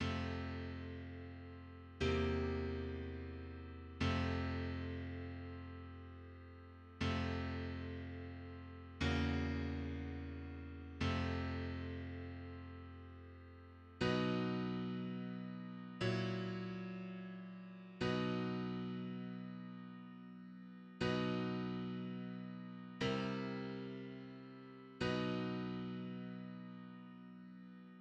Play F-C7-F, F-F7-F, B-F7-B, then B-C7-B
Tritone_substitution_I-V-I.mid.mp3